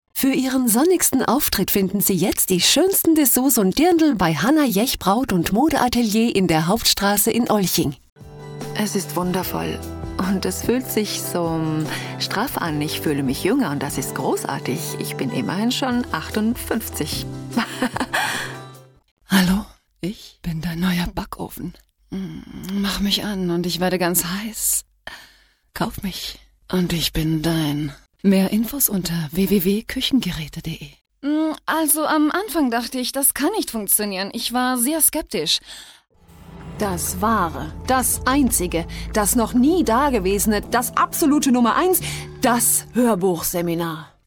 Sprechprobe: Werbung (Muttersprache):
norwegian and german female voice over artist, also excellent English and French. Actress for film, tv and stage